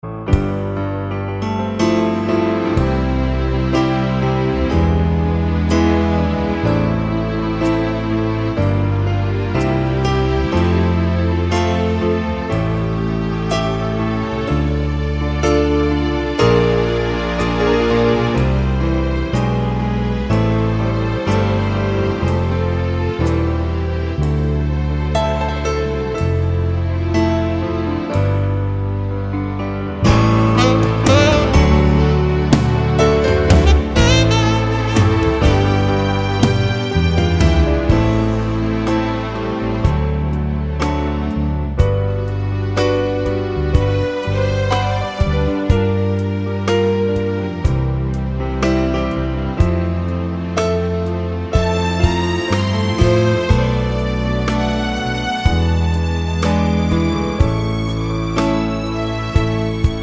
Minus Sax Solo Pop (1980s) 5:20 Buy £1.50